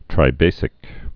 (trī-bāsĭk)